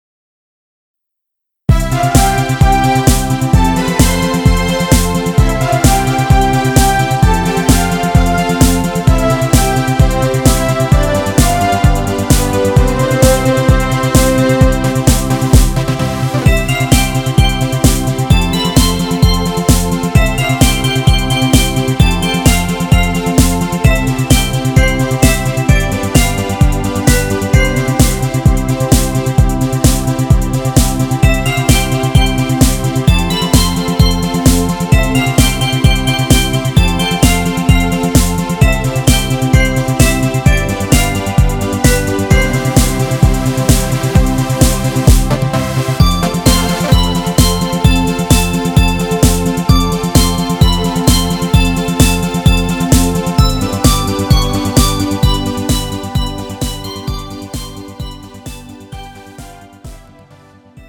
음정 C 원키
장르 가요 구분 Pro MR